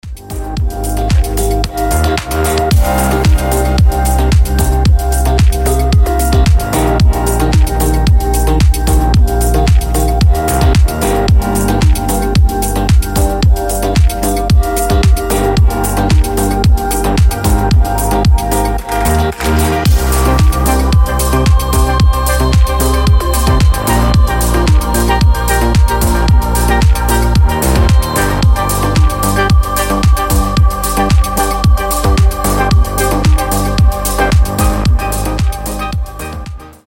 • Качество: 320, Stereo
атмосферные
Electronic
deep progressive
Мистическая музыка